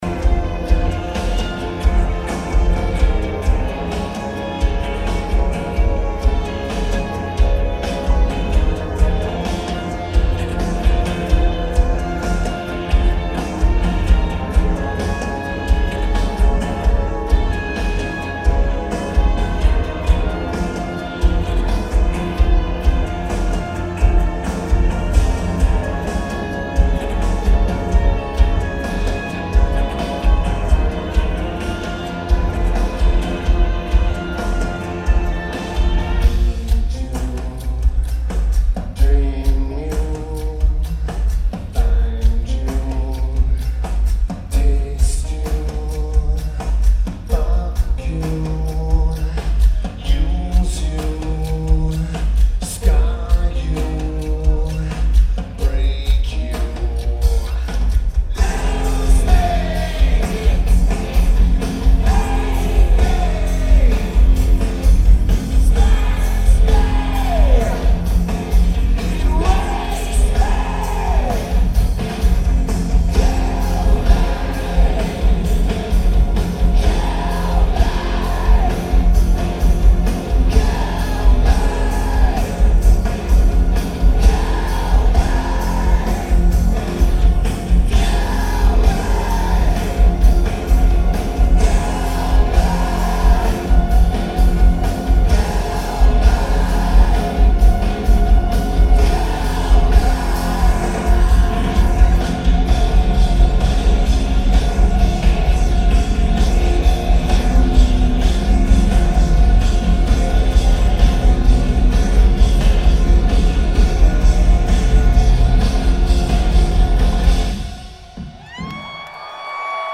Las Vegas, NV United States